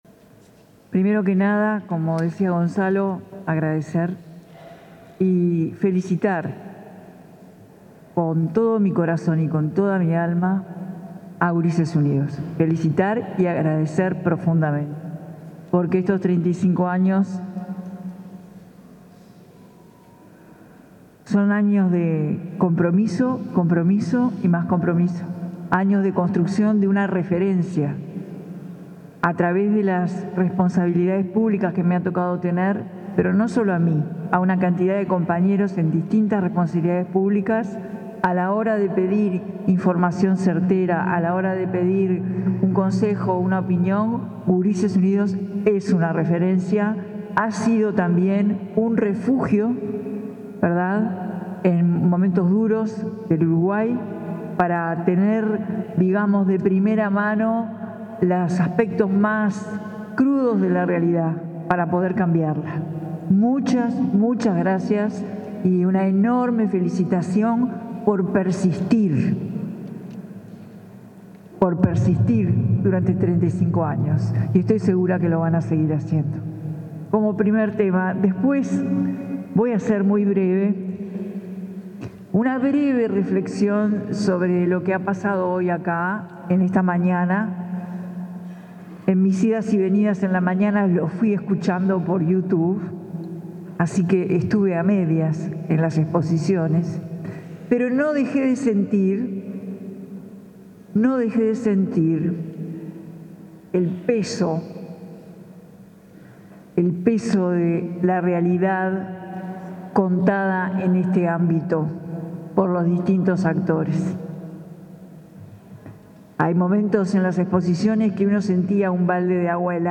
Palabras de la vicepresidenta de la República, Carolina Cosse
Palabras de la vicepresidenta de la República, Carolina Cosse 13/06/2025 Compartir Facebook X Copiar enlace WhatsApp LinkedIn En el conversatorio Infancias, Adolescencias y Crimen Organizado, convocado por la organización no gubernamental Gurises Unidos, se expresó la vicepresidenta de la República, Carolina Cosse.